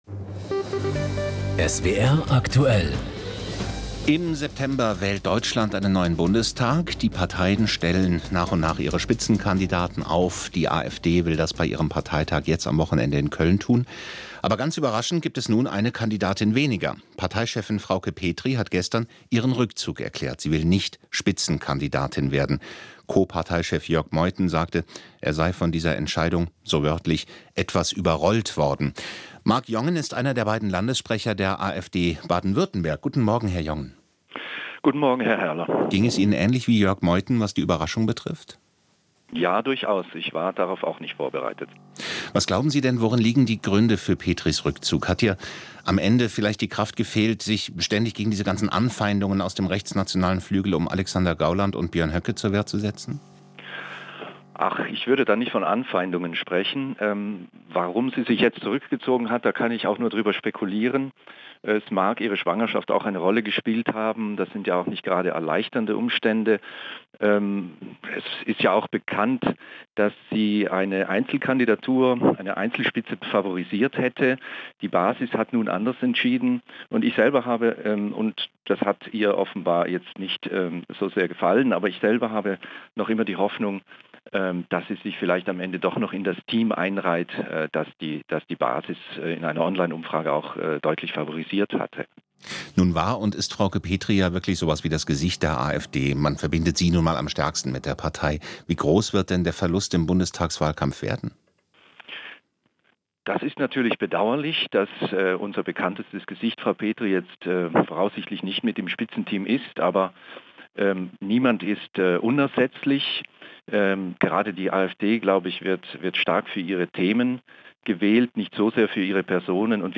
Interview Marc Jongen auf SWR Aktuell zum Verzicht Frauke Petrys auf eine Spitzenkandidatur für den Bundestagswahlkampf 2017 - Marc Jongen